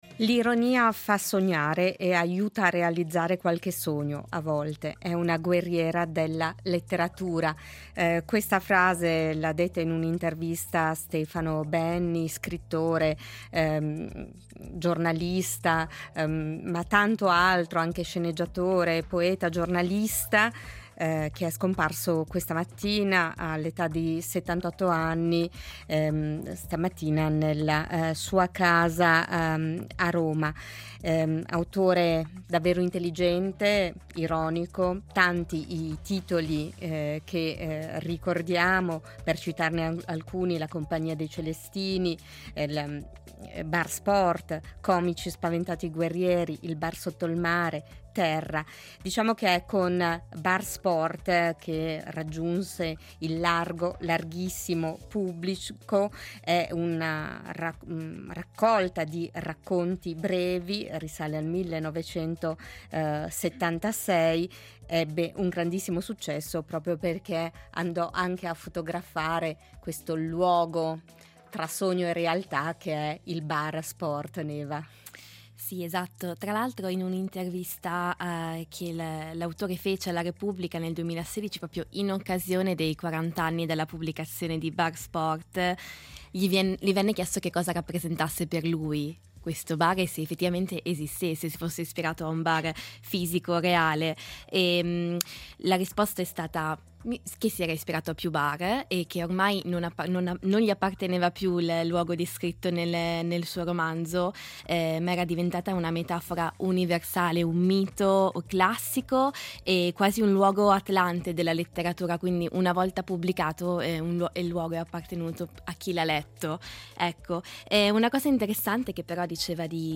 È scomparso oggi lo scrittore di Bar Sport. Una descrizione del libro diventato celebre per la sua ironia e la sua capacità di restituire un luogo “mitico” e la sua voce dagli Archivi RSI.